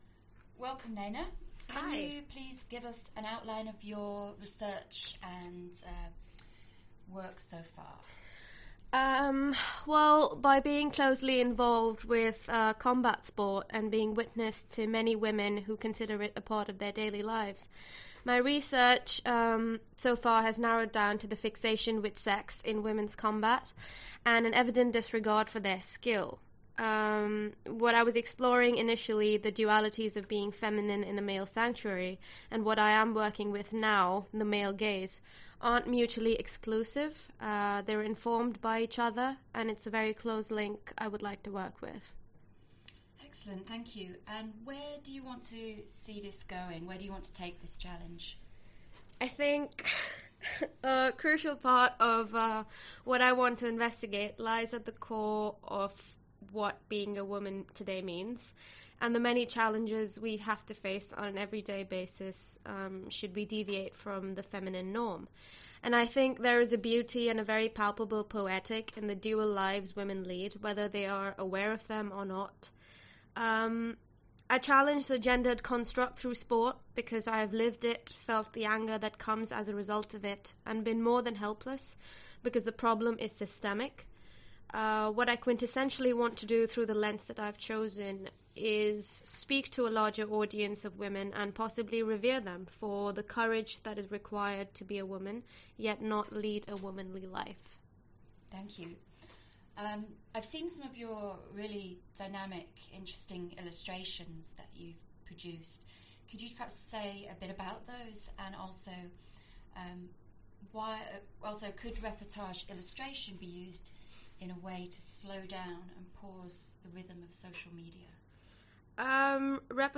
(02. 2018) Graphically Speaking published these ideas via audio interviews of each class member, running as a live broadcast from Central Saint Martins (accessible in-person and online) for the duration of the show.
The audio was broadcast live from the GCD studios in Central Saint Martins and archived online.